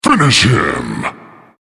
Звуки для троллинга
Звук Finish его